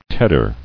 [ted·der]